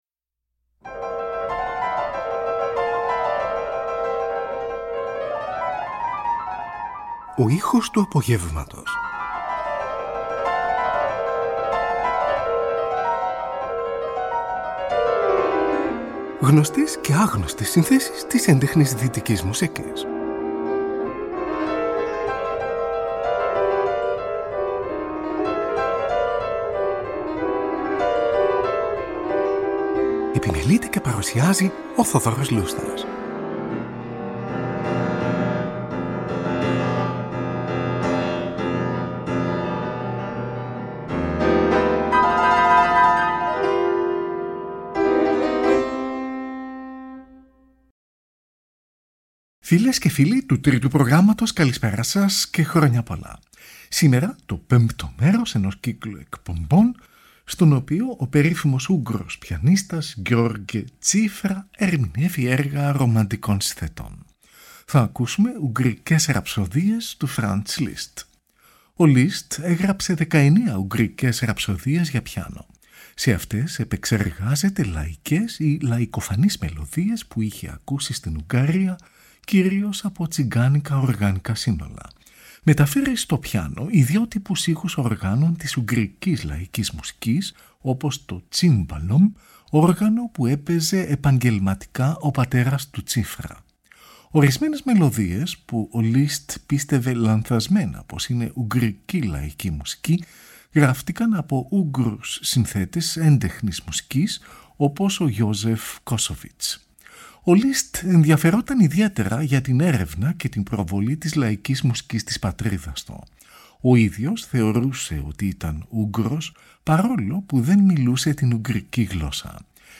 για Πιάνο